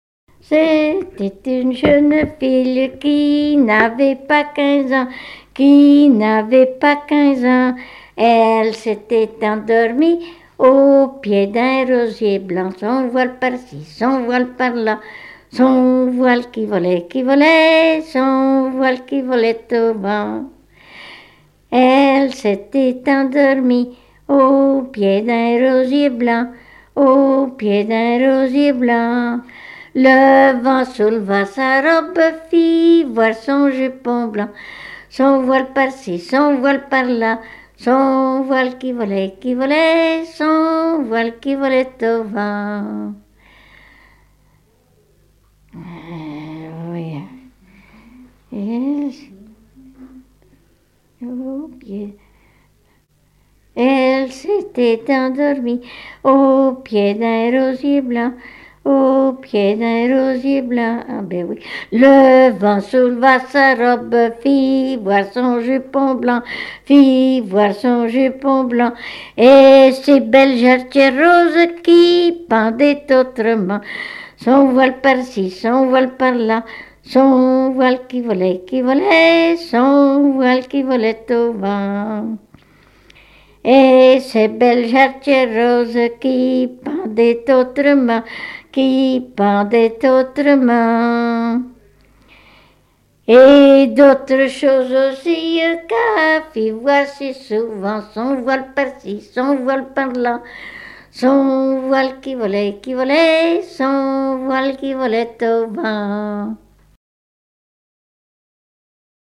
gestuel : à marcher
Chansons traditionnelles
Pièce musicale inédite